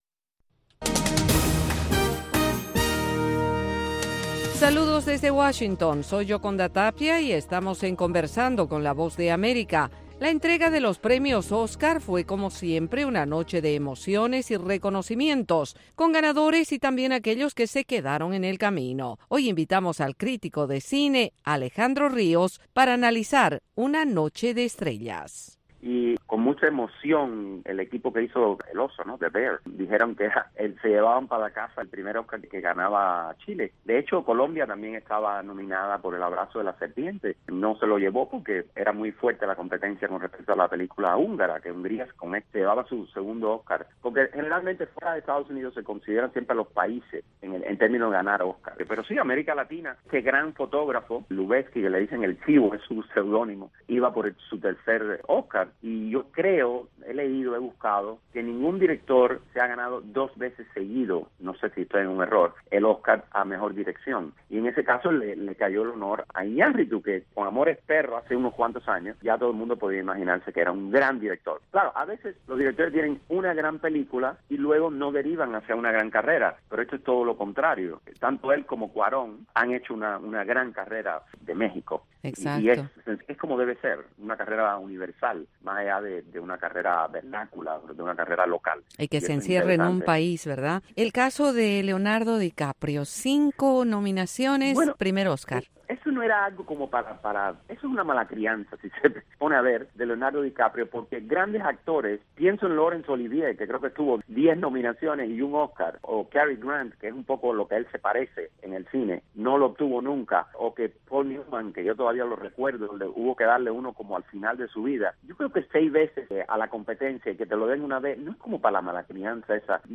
Entrevista VOA - 12:30pm
La Voz de América entrevista, en cinco minutos, a expertos en diversos temas.